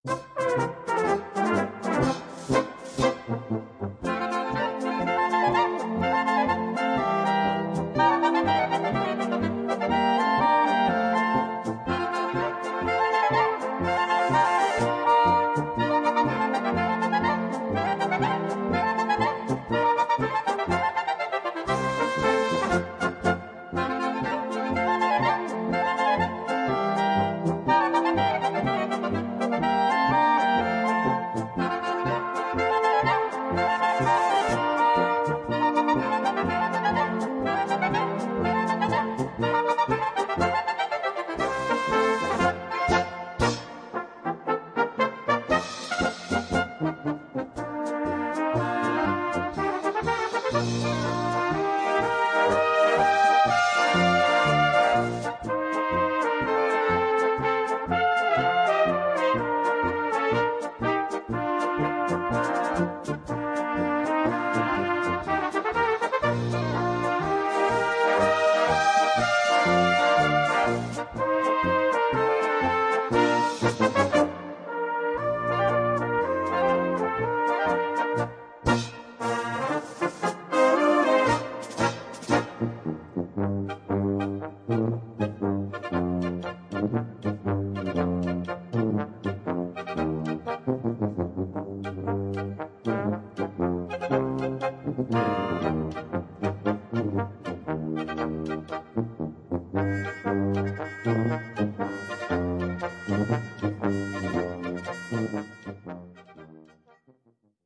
Gattung: Solo für diverse Instumente und Blasorchester
Besetzung: Blasorchester